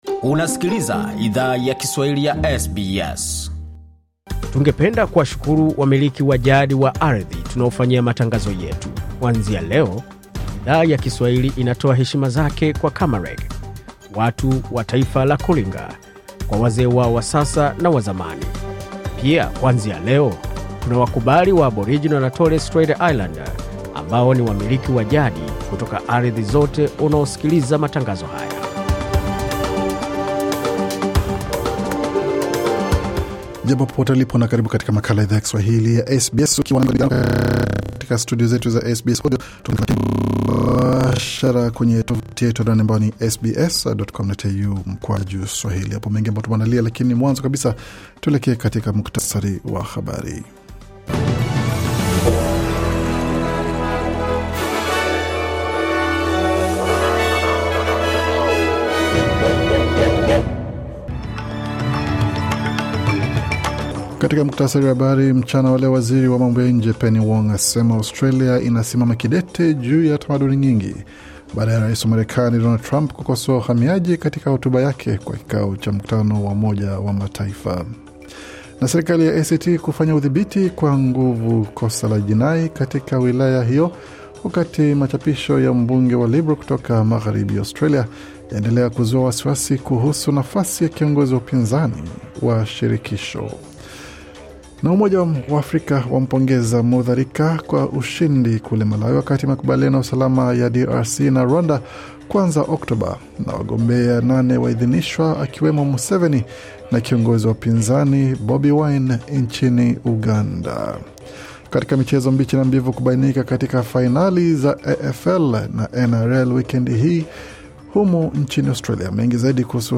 Taarifa ya Habari 26 Septemba 2025